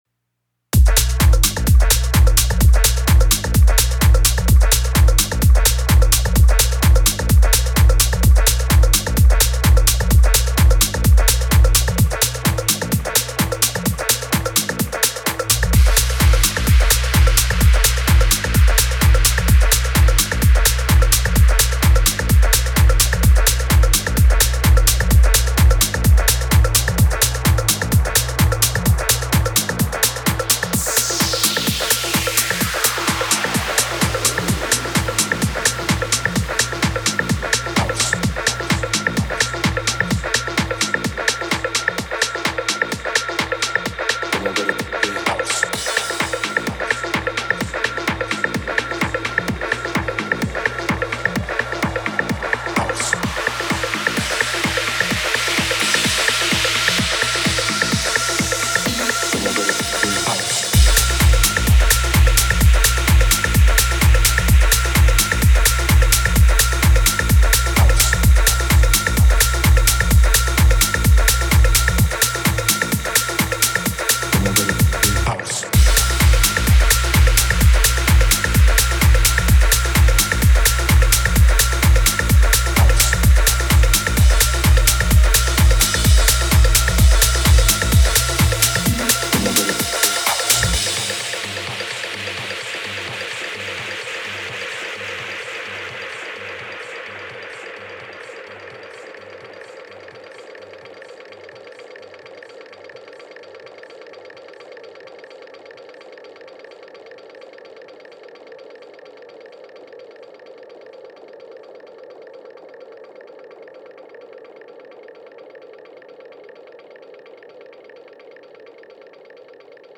I create all the drum loops on the DT2 by loading up banks with similar drums and sweeping through the bank with the LFO to create unique percussion, bass, and synth loops - fun stuff.
The new Visual EQ on the MPC makes a great high and low pass filter btw